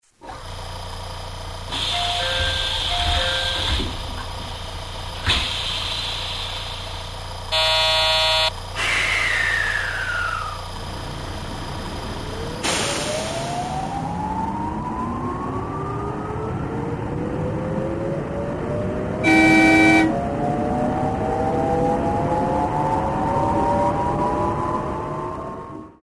東芝製IGBT素子VVVFインバータ制御装置に由来するサウンドや、独特の警笛 (タイフォン+ホイッスル)、空気式ドアエンジン音を再現。
ク音、ATS-PTのチャイム音も忠実に再現。